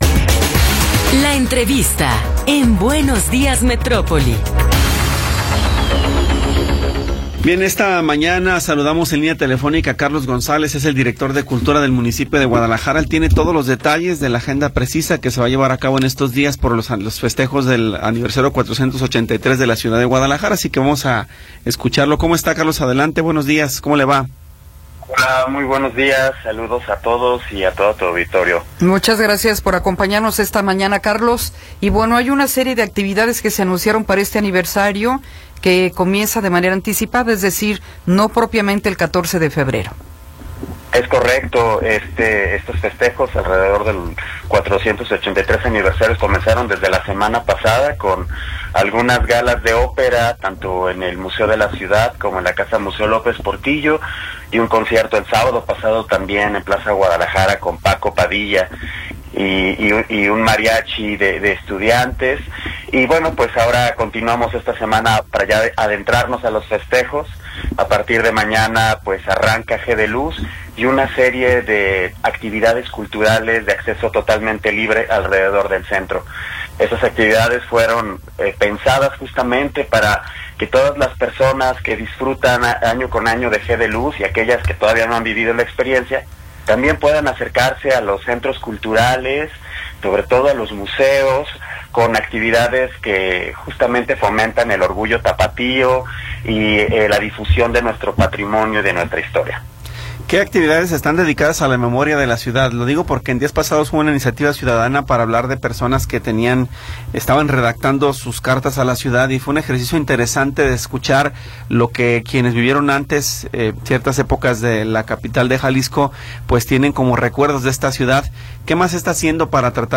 Entrevista con Carlos González Martínez